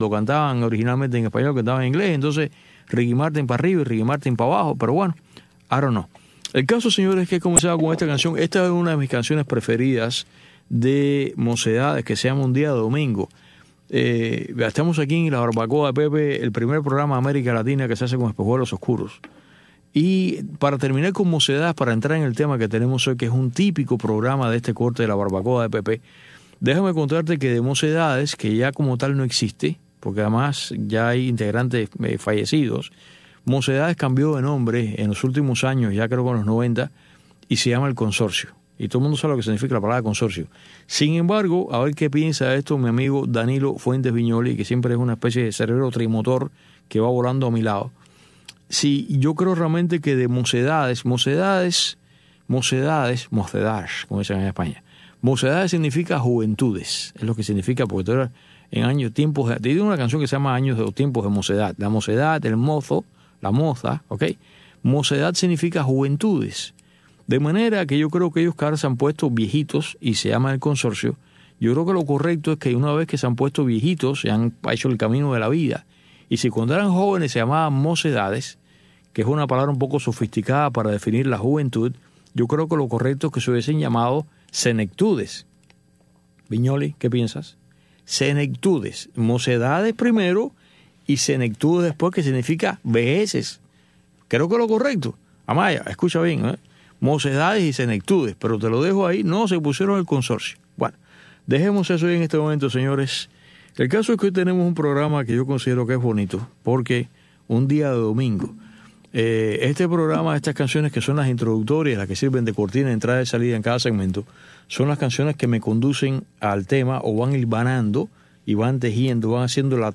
en este espacio informativo en vivo